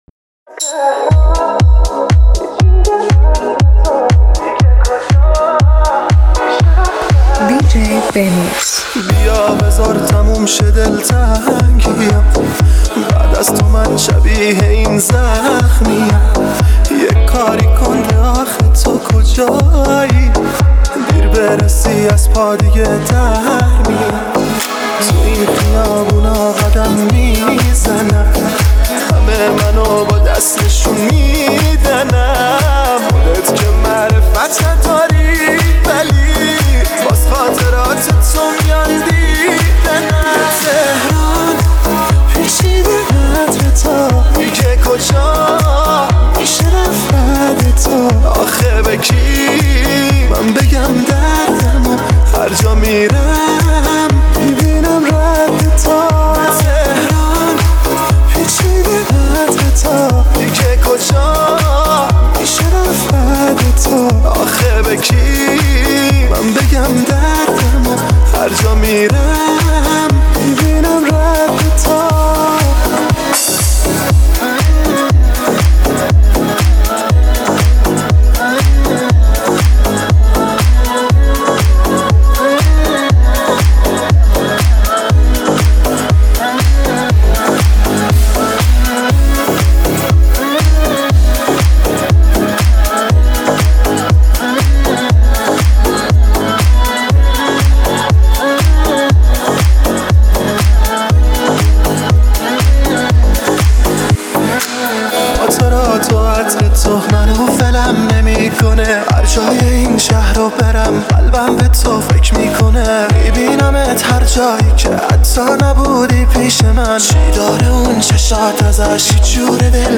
دانلود ریمیکس شاد و پرهیجان آهنگ
یه ریمیکس خفن و پرانرژی که ضربان قلب رو بالا می‌بره!